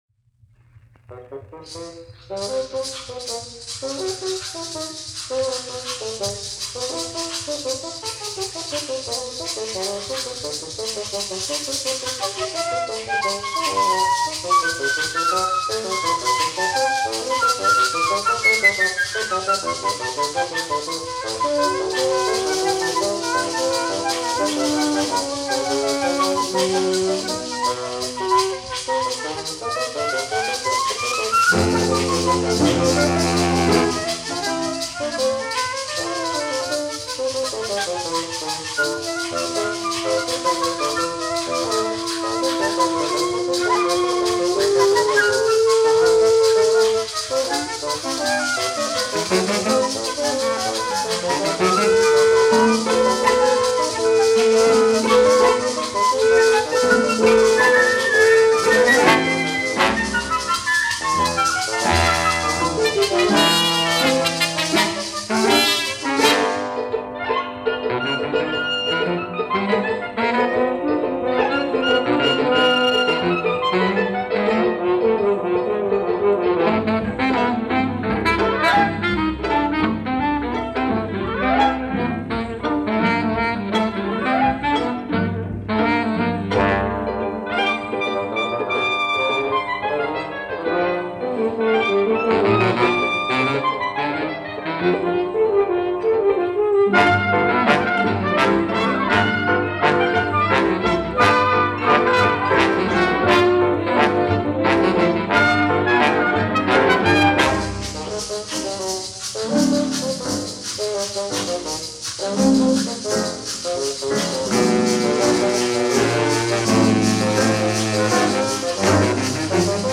here is that 1943 broadcast performance